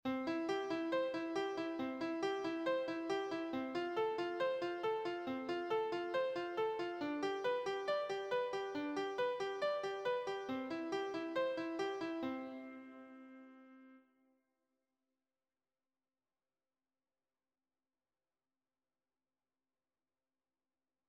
Escucha una secuencia I-IV-V-I en Do Mayor utilizando únicamente arpegios:
Audio de elaboración propia. Secuencia con arpegios. (CC BY-NC-SA)
Arpegios.mp3